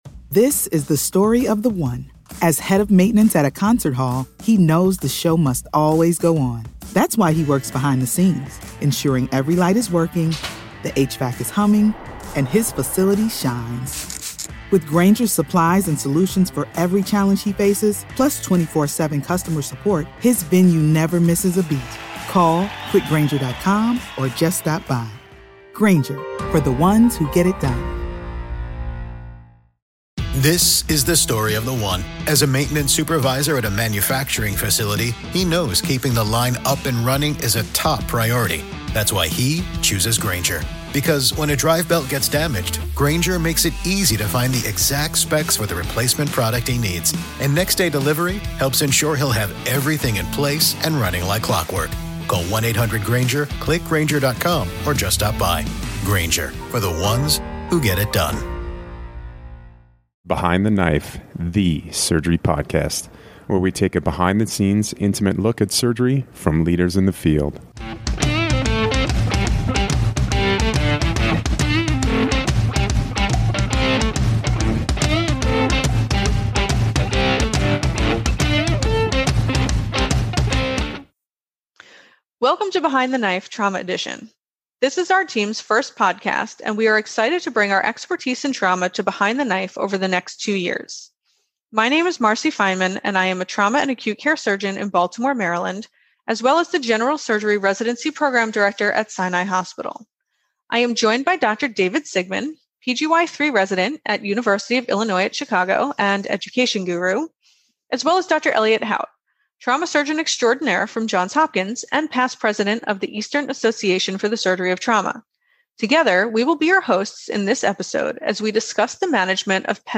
In this episode, the BTK trauma team discusses your options and gives you a few pro tips along the way.